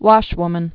(wŏshwmən, wôsh-)